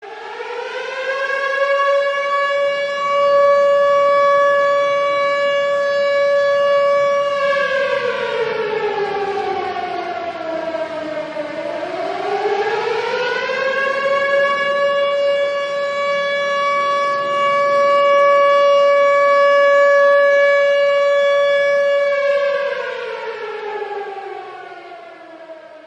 Klingelton Fliegeralarm
Kategorien Soundeffekte